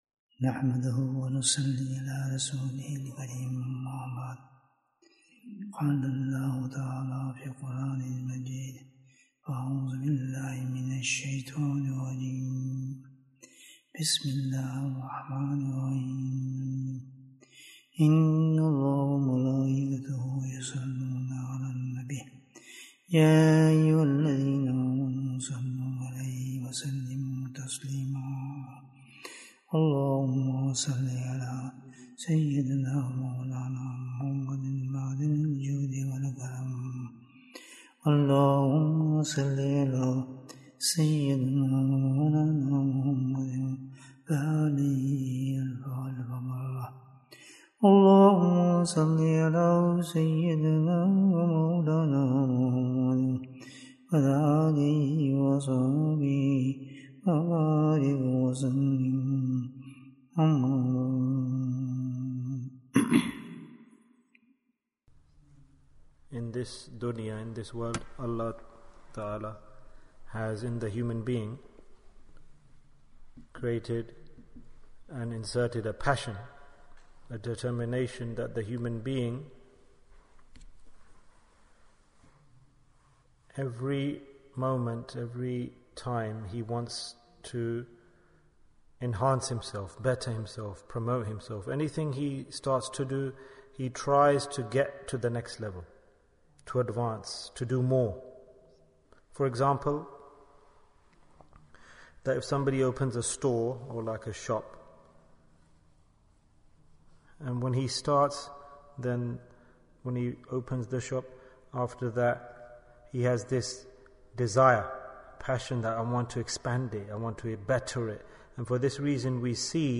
What is the Secret for Success in Tasawwuf? Bayan, 61 minutes24th November, 2022